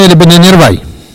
ambiance et archives
Catégorie Locution